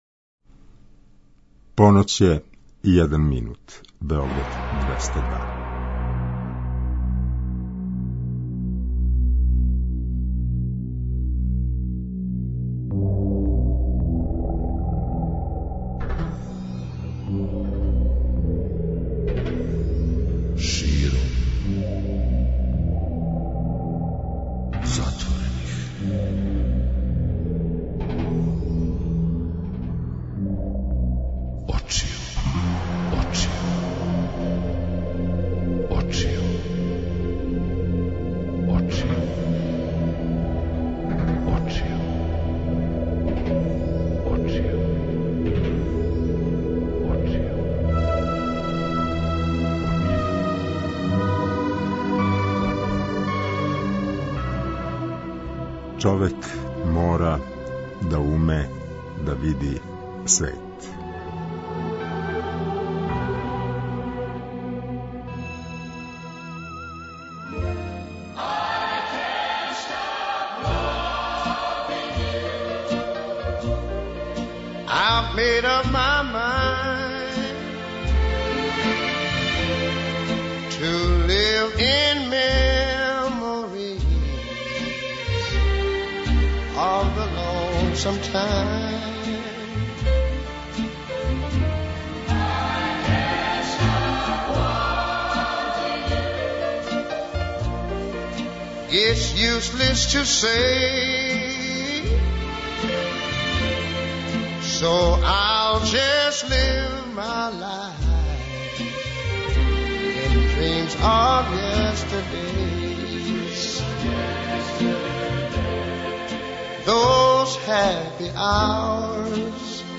Dobro došli u „Samo srcem se dobro vidi“ Beograda 202, u emisiju koju krase izuzetan izbor muzike i vatromet sjajnih razgovara ljudi koji veruju u Beograd 202.